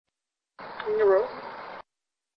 Two Class B EVP's were captured during this mini-investigation.
# 1 Here is the slightly cleaned and amplified version of the EVP:
Cape_Cemetery_1_EVP_Clean.mp3